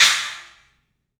Index of /90_sSampleCDs/E-MU Producer Series Vol. 5 – 3-D Audio Collection/3DPercussives/3DPACymbals